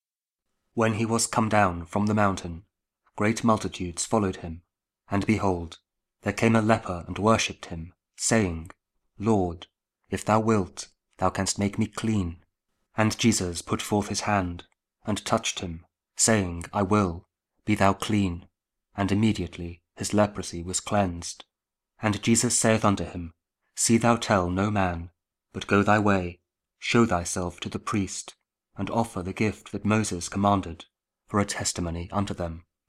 Matthew 8: 1-4 – Week 12 Ordinary Time, Friday (King James Audio Bible KJV, Spoken Word)